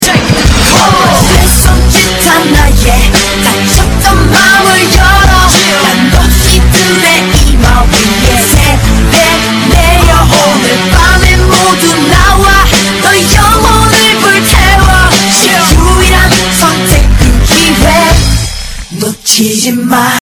分类: DJ铃声